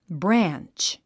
発音
brǽntʃ　ブラァンチ